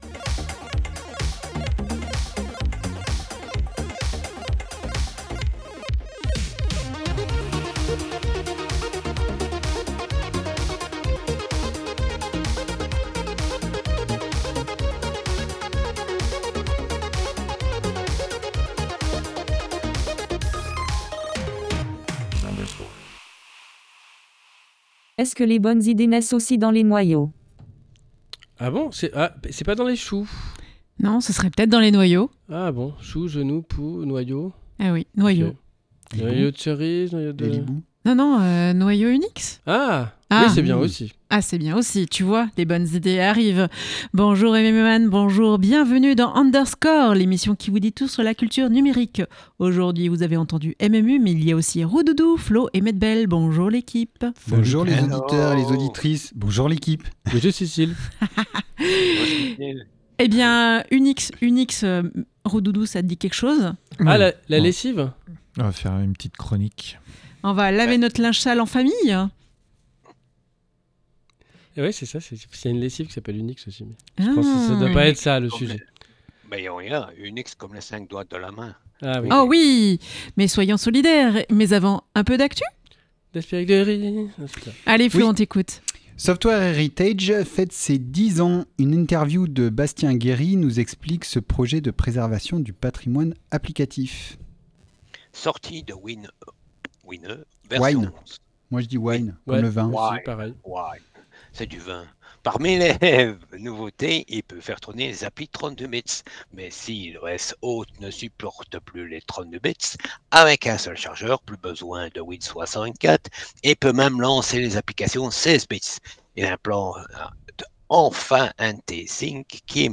Actu